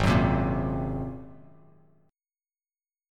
Ab9 Chord
Listen to Ab9 strummed